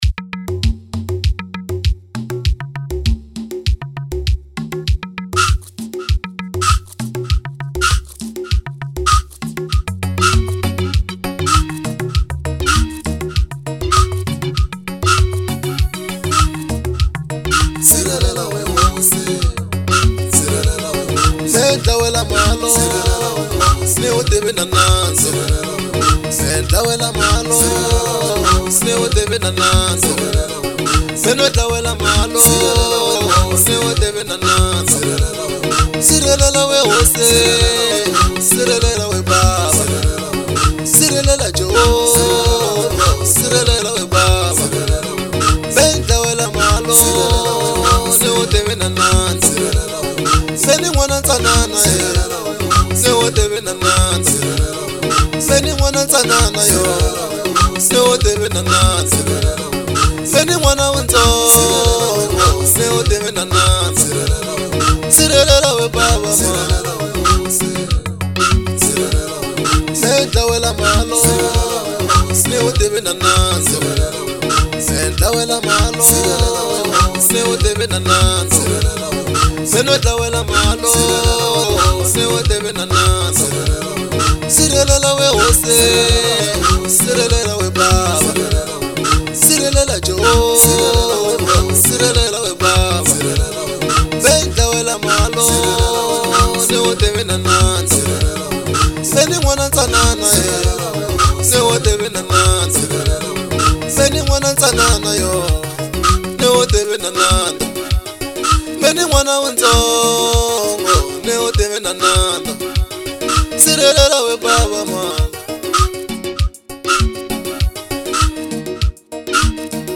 04:11 Genre : Gospel Size